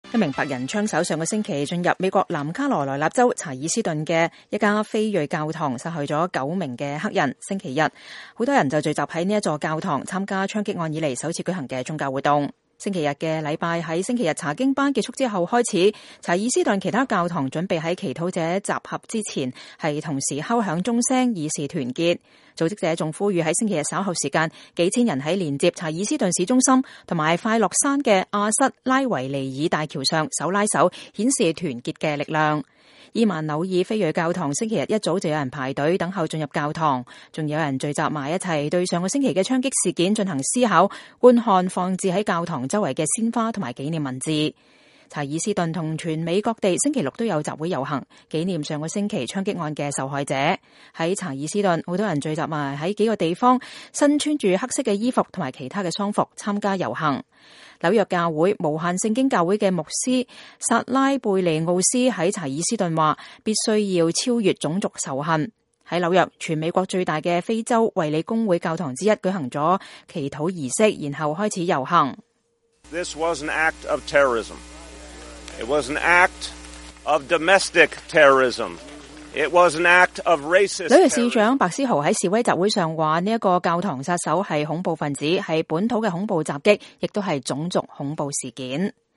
美國南卡查爾斯頓的非裔AME教堂在槍擊事件之後首次舉行宗教活動，教堂外聚集大批群眾。